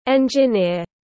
Engineer /ˌendʒɪˈnɪr/